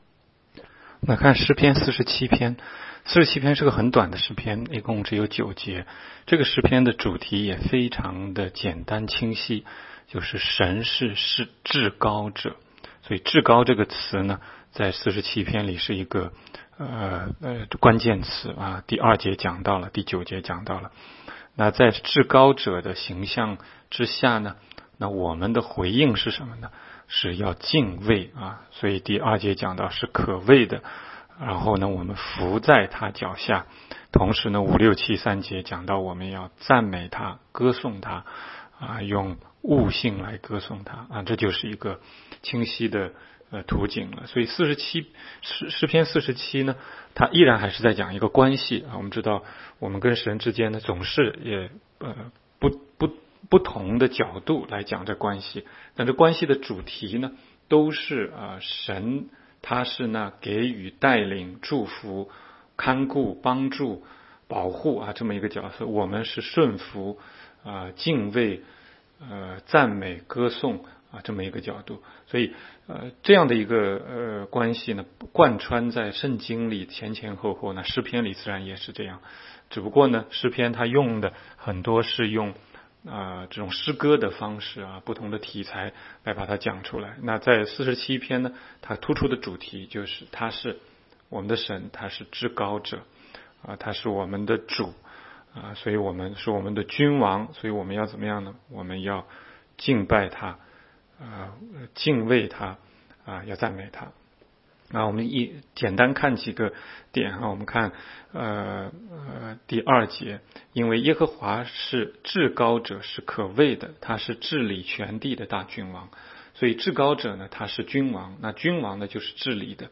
16街讲道录音 - 每日读经-《诗篇》47章